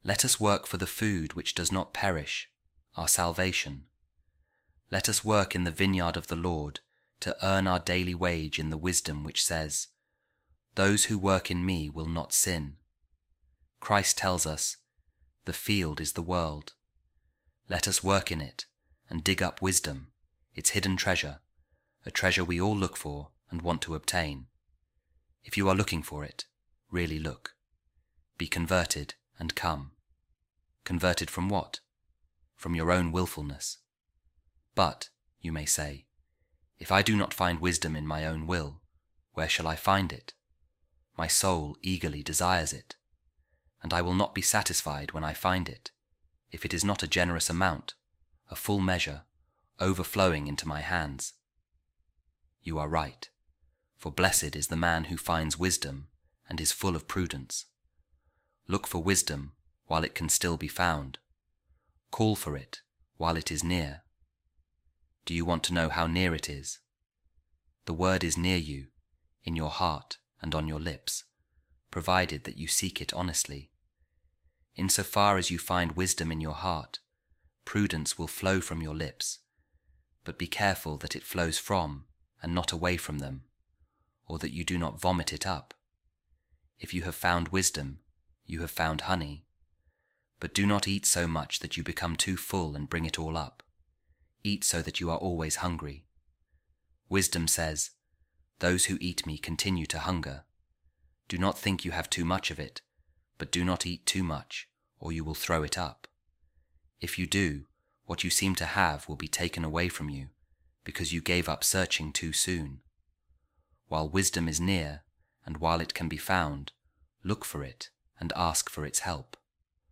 A Reading From A Sermon Of Saint Bernard | The Pursuit Of Wisdom